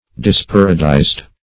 Disparadised \Dis*par"a*dised\, a.